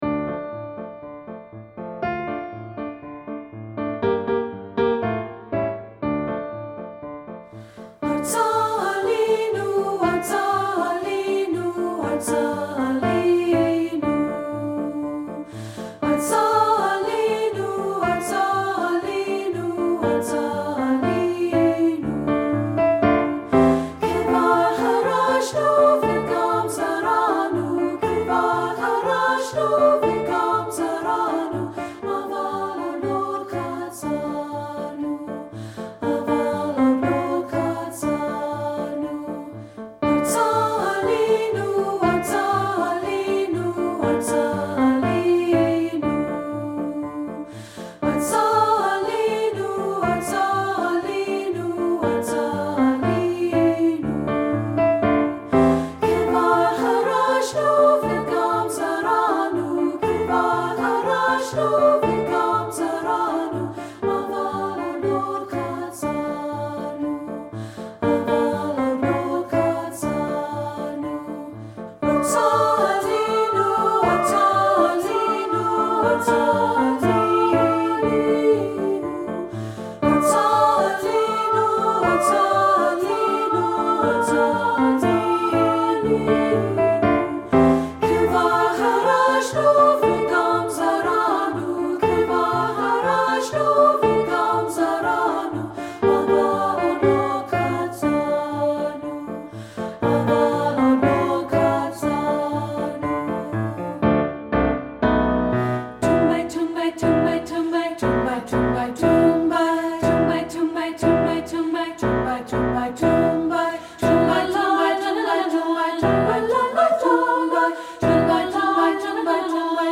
Composer: Israeli Dance Song
Voicing: SA